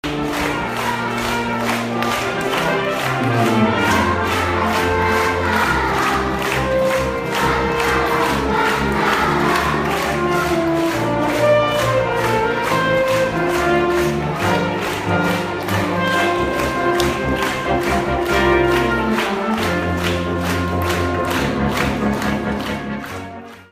東京藝術大学の学生６名が来校し、金管ア ンサンブルをきかせてもらいました。
響きの良さを感じながら、手拍子や体を動 かしたくなるような「音を楽しむ」時間を 過ごしました。